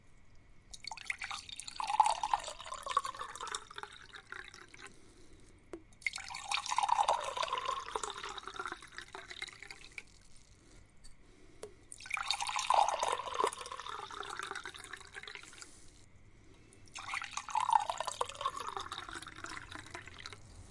浇灌
描述：玻璃杯的声音被充满了。
标签： 汩汩 胶水 咕噜 湿 飞溅 玻璃 倒入 流量 液体 漏极 浇注
声道立体声